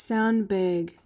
PRONUNCIATION: (SAND-bag) MEANING: noun:A bag filled with sand. verb tr.:1.